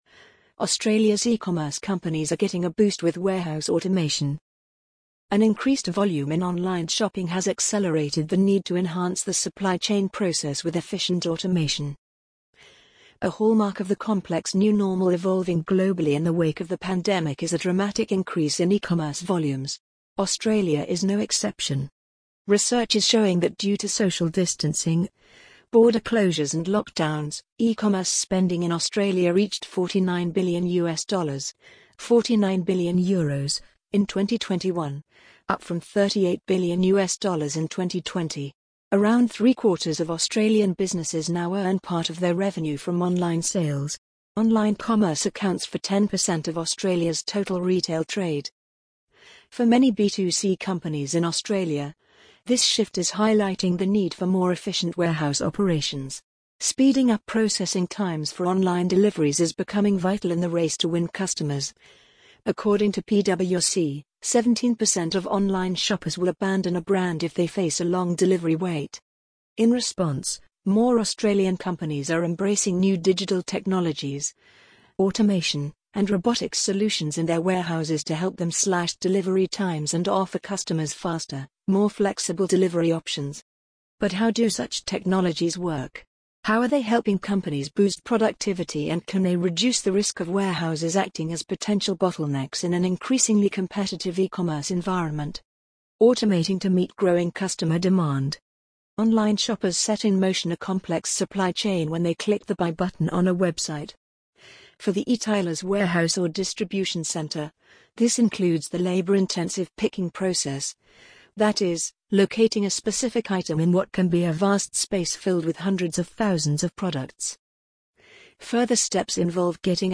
amazon_polly_33340.mp3